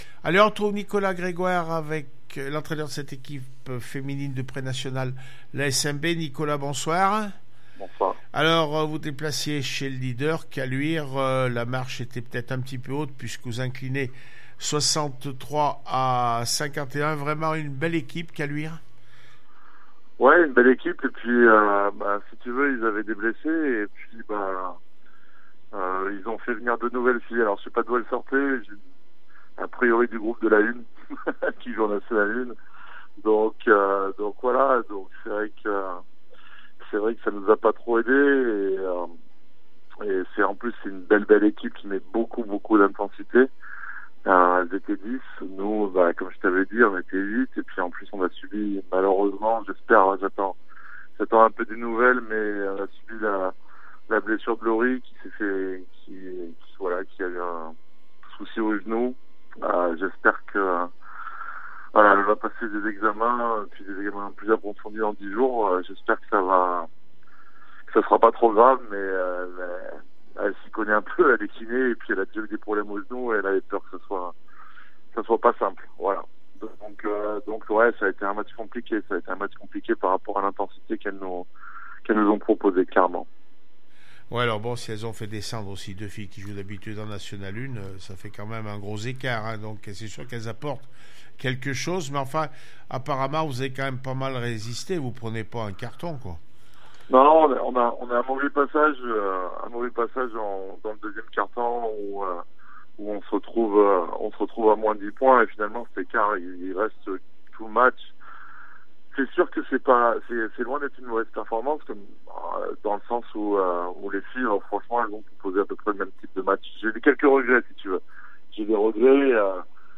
basket féminin aura prenationale Caluire 63-51 asmb le puy réac après match 200120